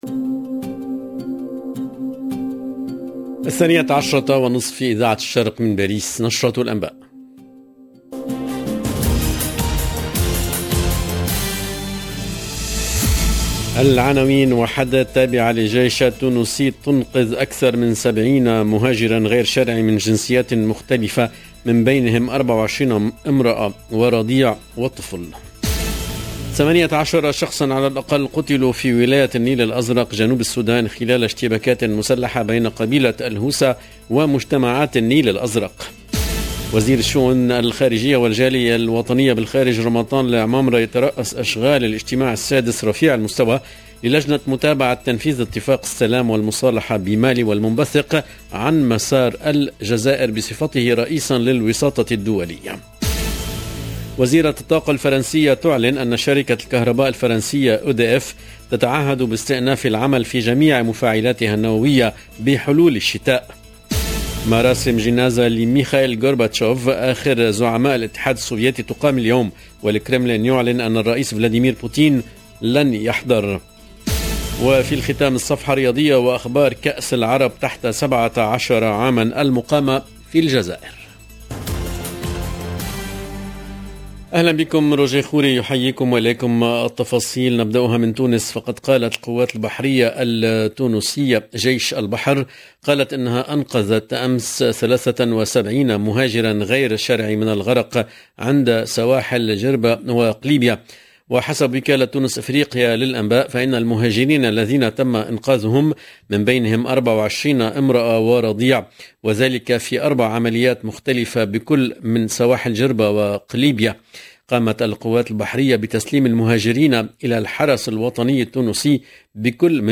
EDITION DU JOURNAL DE 13H EN LANGUE ARABE DU 3/9/2022